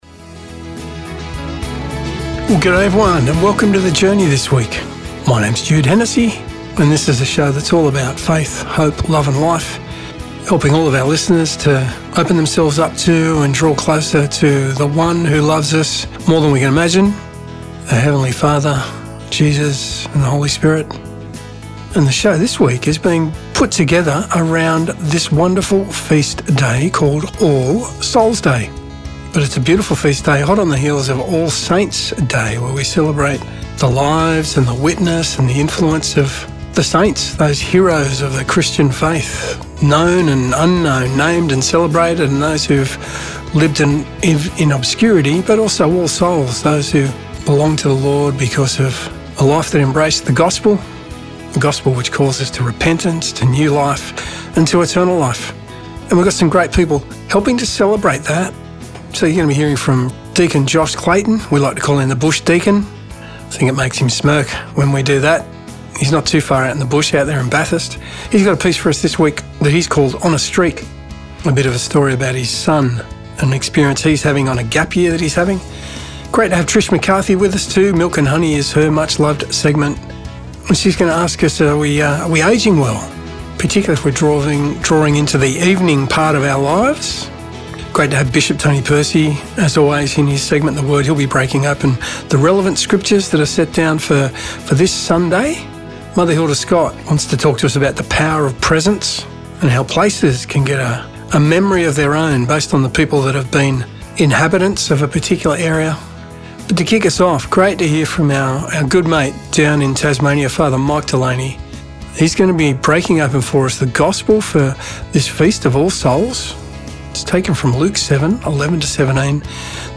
"The Journey" is a weekly one hour radio show produced by the Catholic Diocese of Wollongong and aired on various Christian Radio Stations around Australia
Each week, there is a reflection on the Sunday Gospel reading. Add to that some great music and interviews with people doing amazing things right around the globe and you’ve got a show that is all about faith, hope love and life.